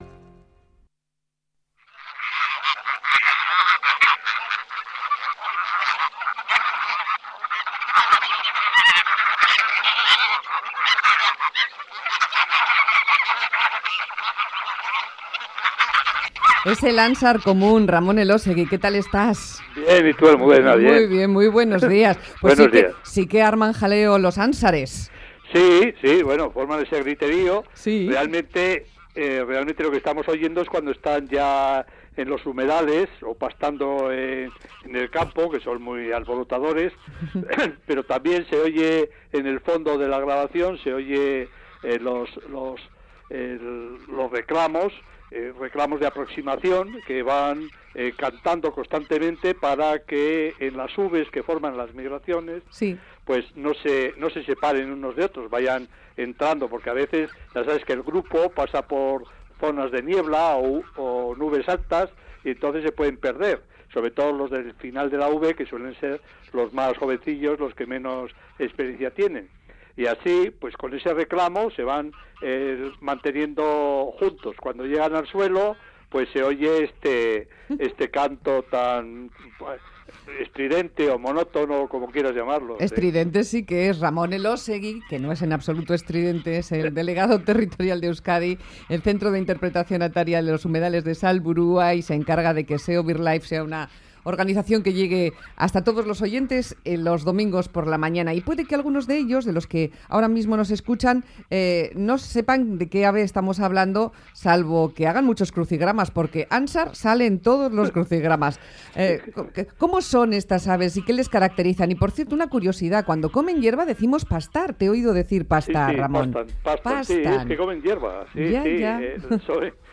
Radio Euskadi PAJAROS Hablamos del ánsar común Última actualización